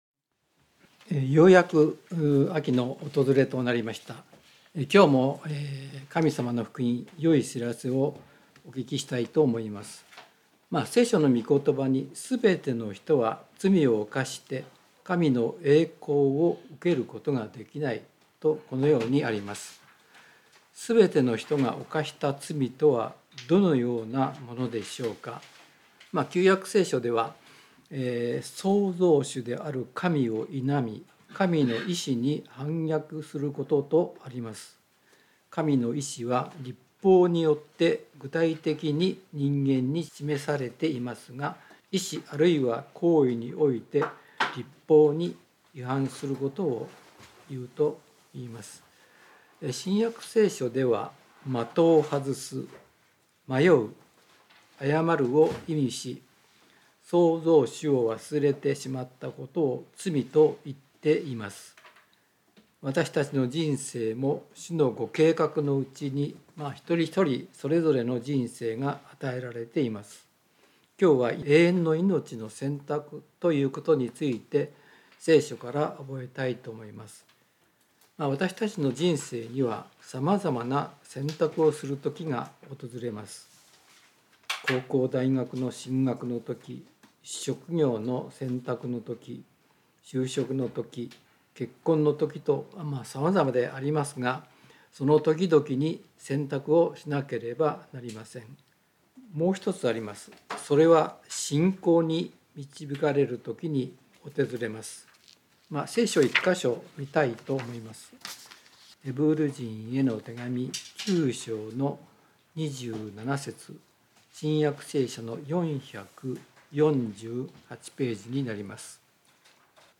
聖書メッセージ No.290